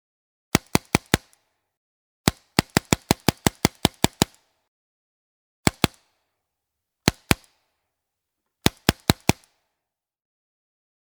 Airguns
• Category: Pneumatics